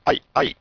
Encolhendo o sinal no tempo (sem alterar seu conteúdo harmônico)
aiEncolhido.wav